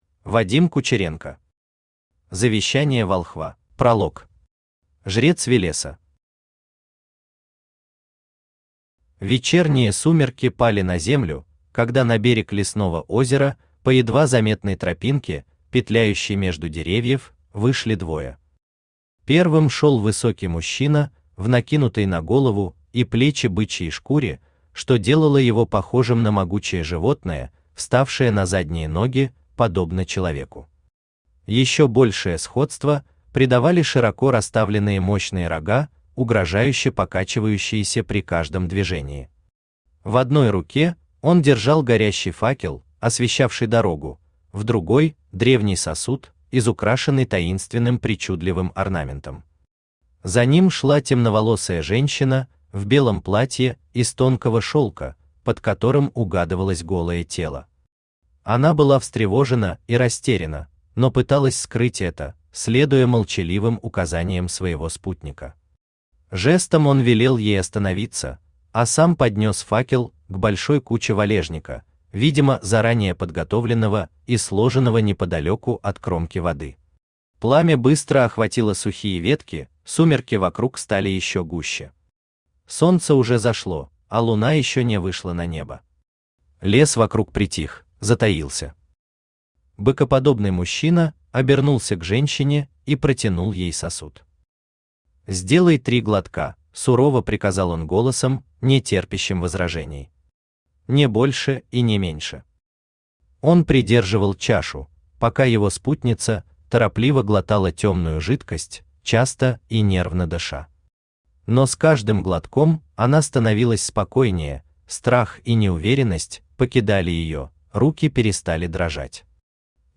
Аудиокнига Завещание волхва | Библиотека аудиокниг
Aудиокнига Завещание волхва Автор Вадим Иванович Кучеренко Читает аудиокнигу Авточтец ЛитРес.